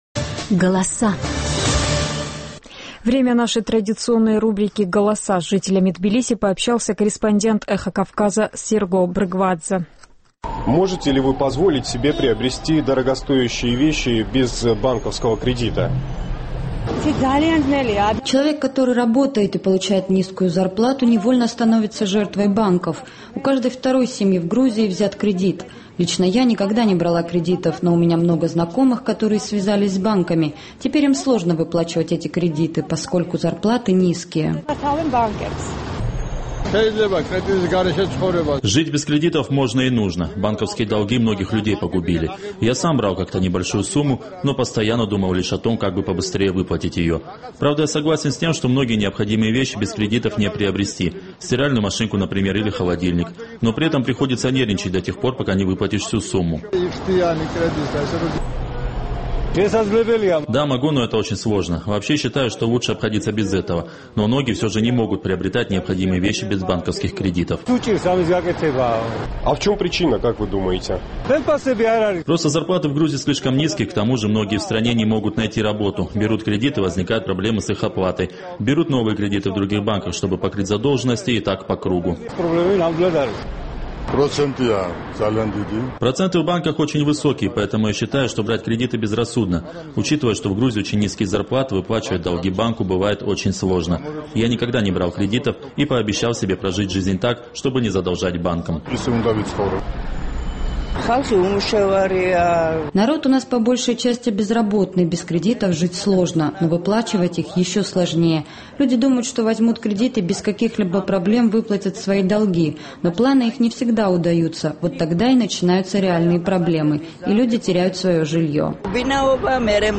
Наш тбилисский корреспондент интересовался у граждан Грузии, как часто берут банковские кредиты и стоит ли вообще связываться с банками.